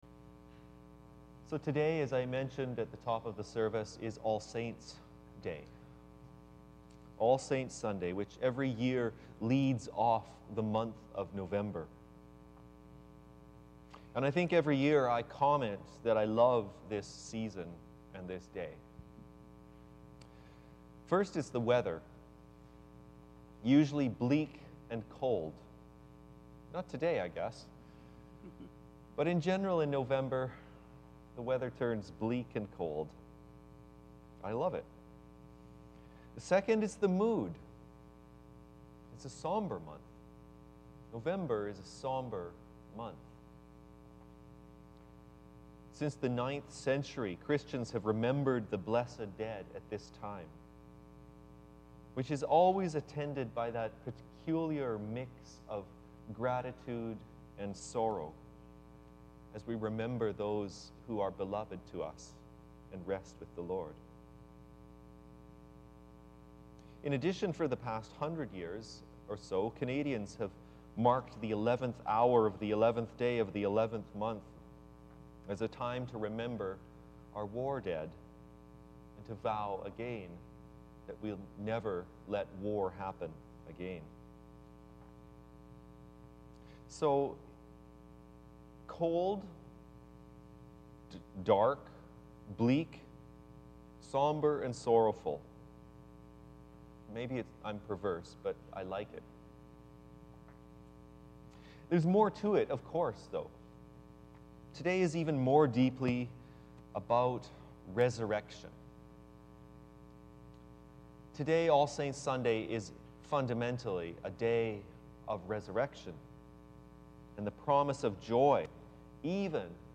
a sermon for All Saints